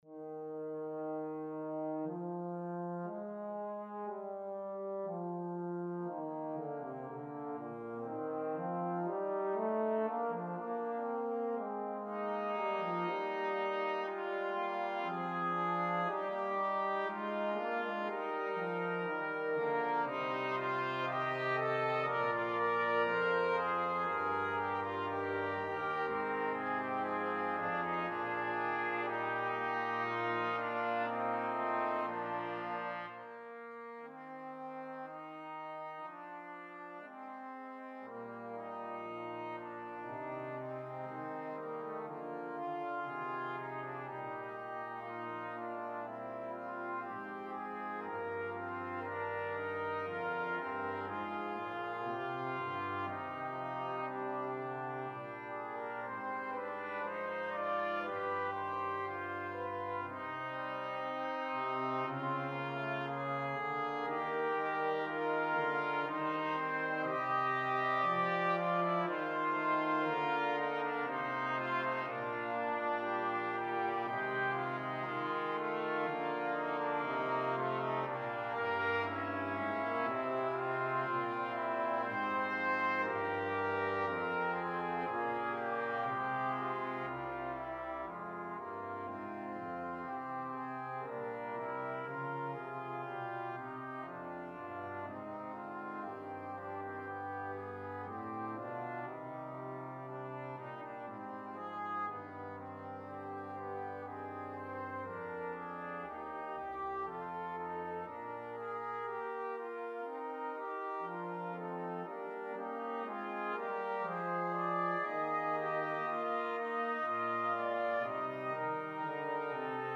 Besetzung: Brass Quartet (2Crt, Hrn, Euph)